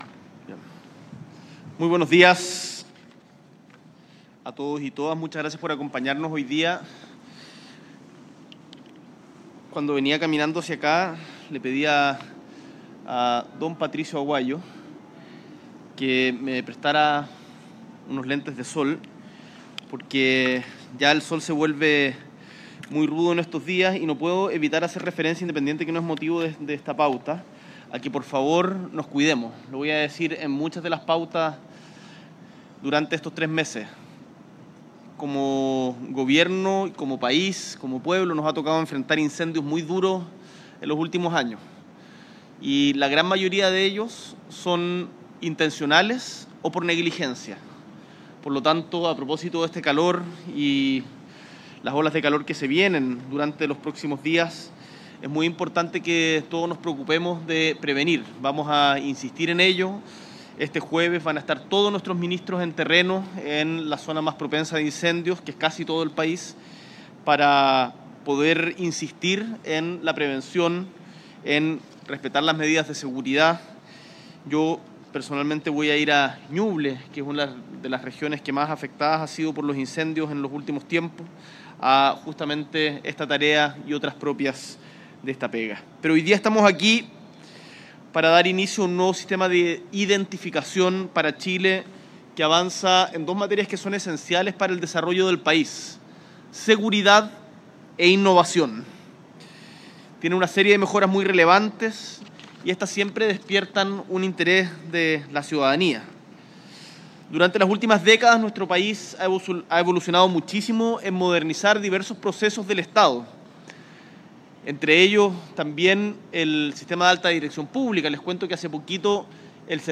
Presidente Gabriel Boric encabeza el lanzamiento del Nuevo Sistema de Identificación Nacional